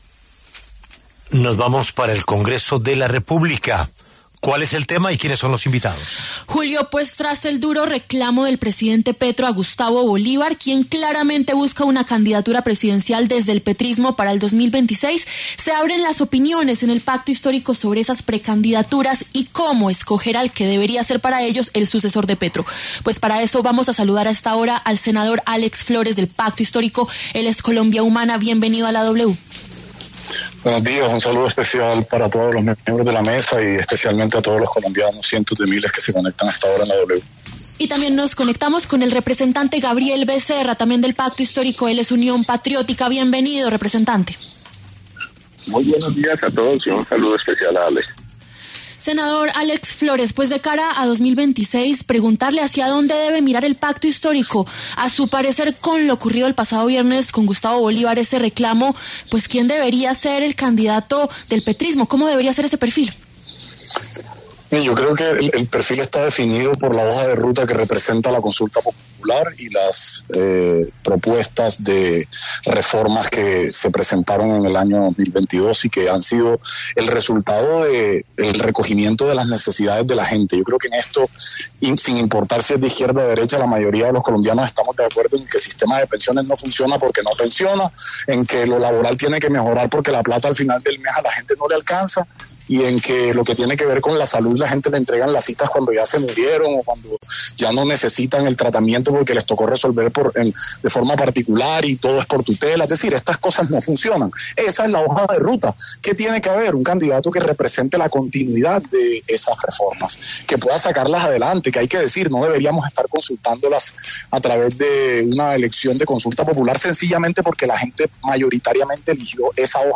Los congresistas Alex Flórez y Gabriel Becerra hablaron sobre el candidato del petrismo para el 2026, tras el fuerte reclamo del presidente Gustavo Petro a Gustavo Bolívar.
Debate: ¿hacia dónde debe mirar el Pacto Histórico para 2026 tras el regaño a Gustavo Bolívar?